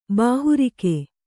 ♪ bāhurike